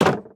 Minecraft Version Minecraft Version latest Latest Release | Latest Snapshot latest / assets / minecraft / sounds / block / fence_gate / close1.ogg Compare With Compare With Latest Release | Latest Snapshot
close1.ogg